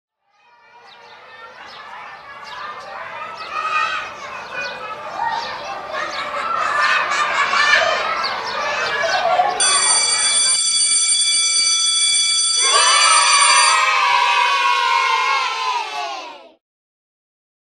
Sonido de inicio personalizado
El sonido de inicio reemplaza al sonido por defecto del sistema.
AL_sound.oga — Sonido de inicio
Reproducido al iniciar sesión · formato OGG Vorbis